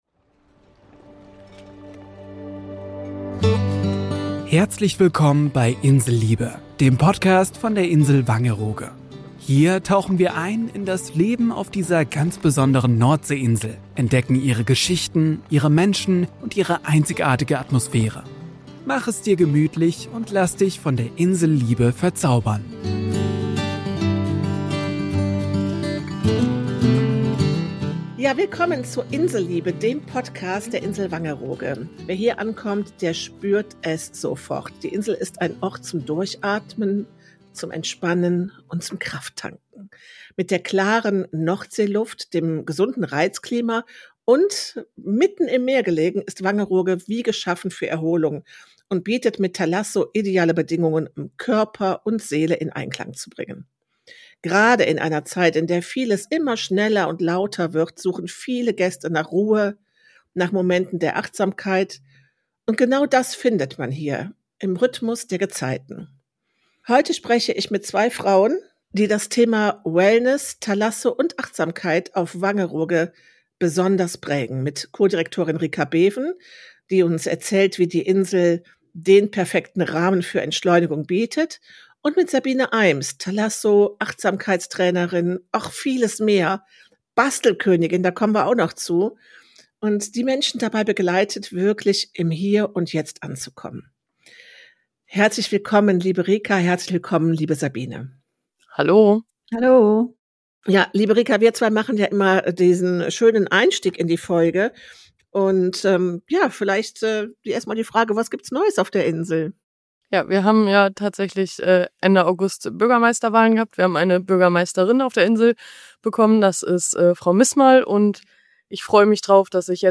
Eine Kostprobe davon gibt es sogar exklusiv als geführte Klangschalen-Meditation am Ende des Podcasts.